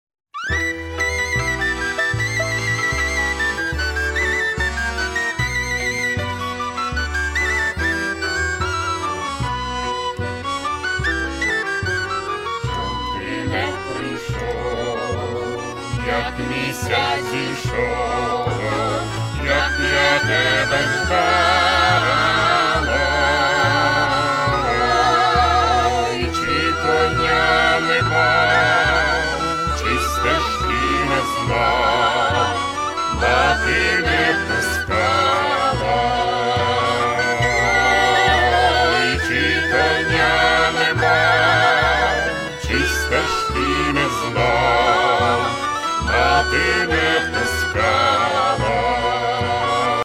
Початок » CDs» Народна Мій аккаунт  |  Кошик  |  Замовити